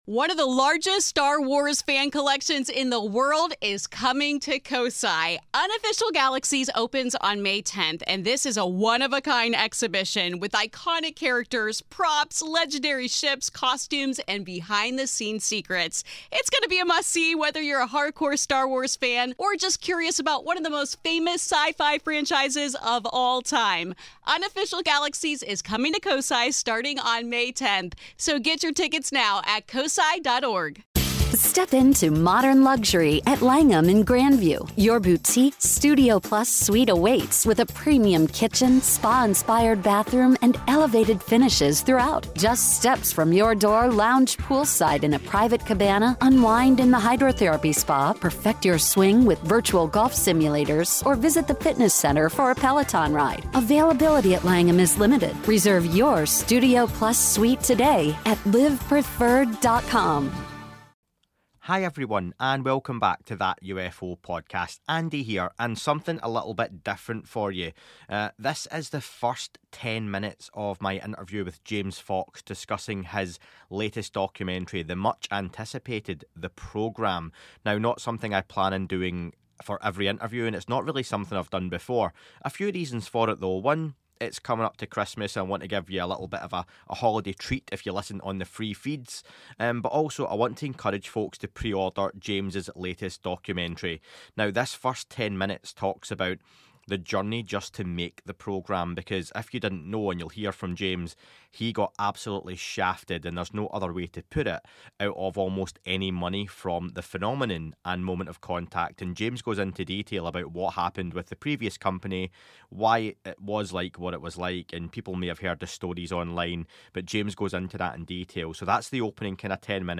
Enjoy the first ten minutes of my interview